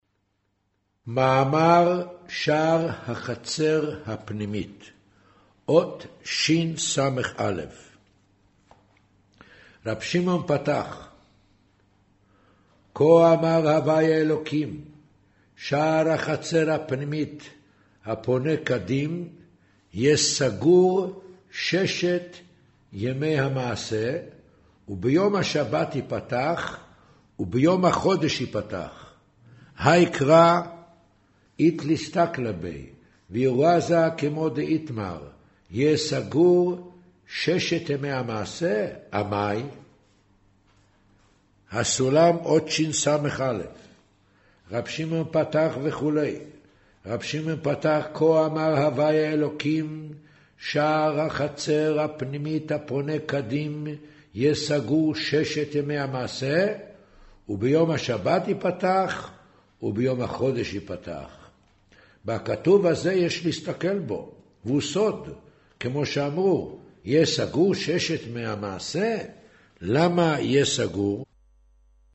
קריינות זהר, פרשת נח, מאמר שער החצר הפנימית